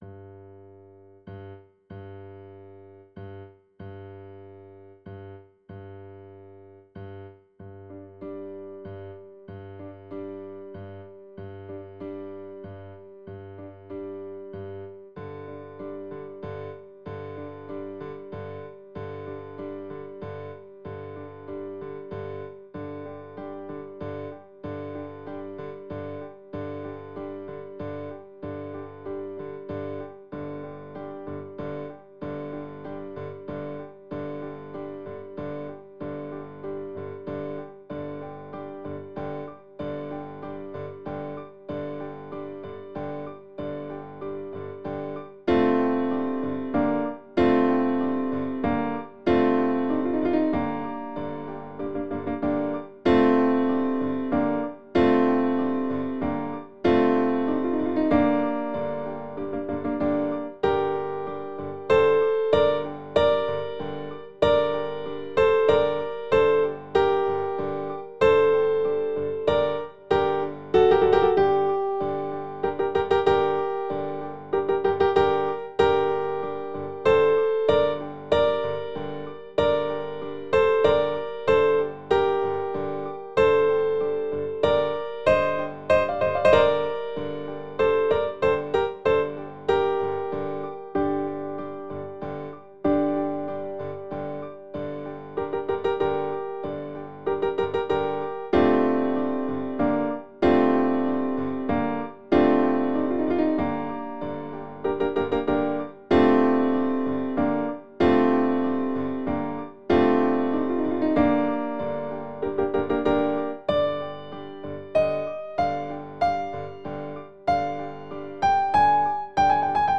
Listen to Ensemble